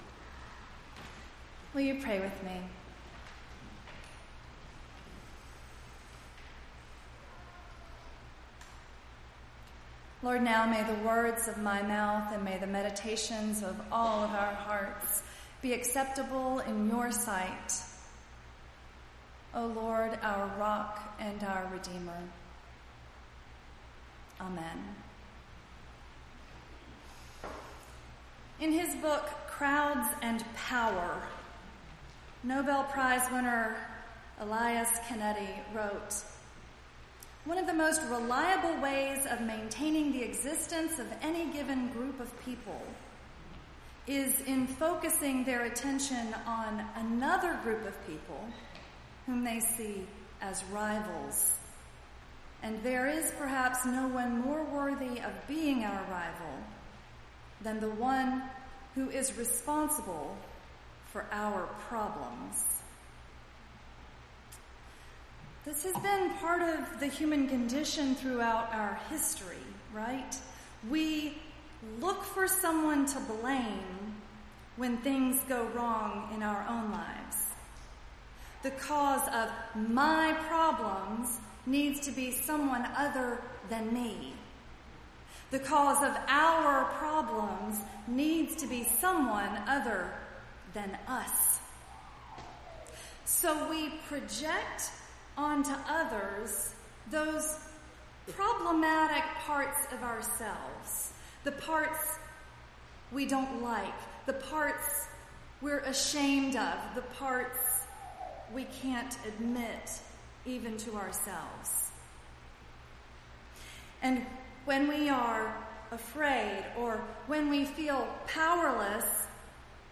9-2-18-sermon.mp3